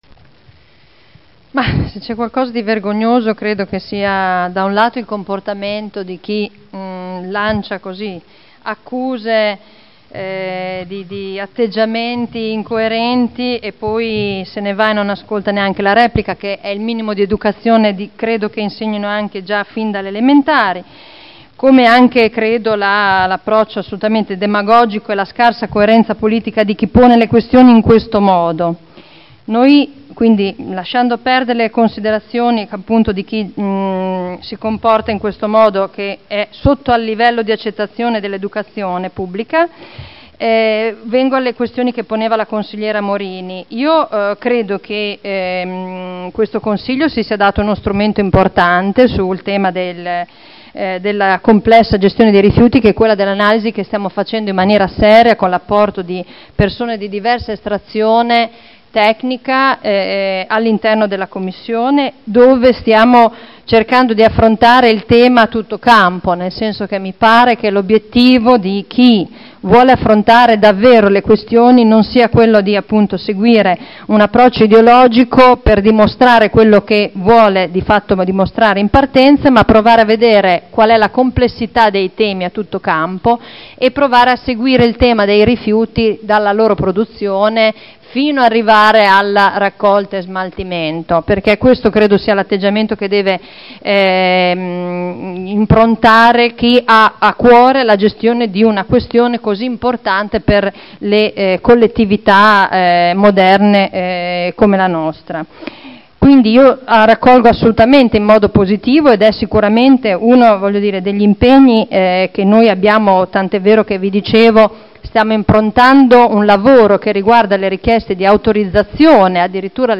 Simona Arletti — Sito Audio Consiglio Comunale